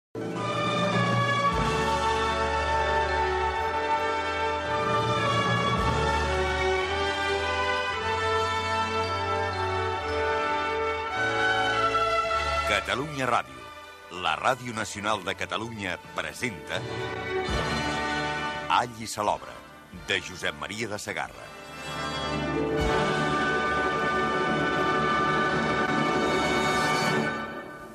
Careta de l'adaptació radiofònica